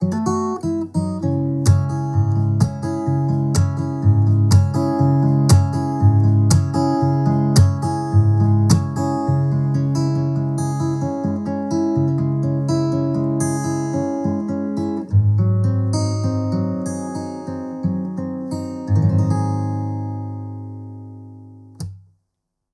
Échantillons sonores Audio Technica AE-5100
Audio Technica AE5100 - gitara akustyczna